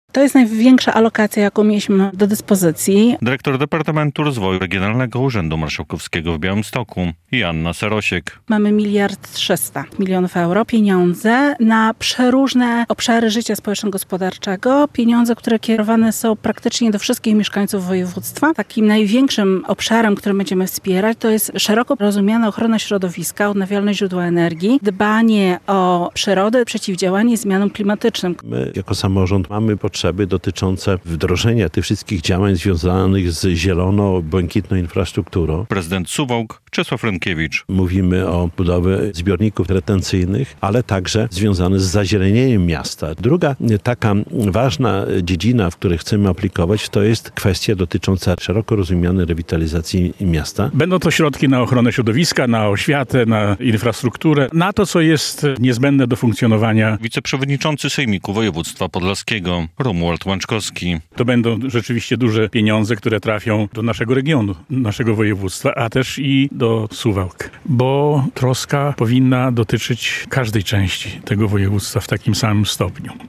O pieniądzach dla samorządów z nowego programu regionalnego na konferencji w Suwałkach - relacja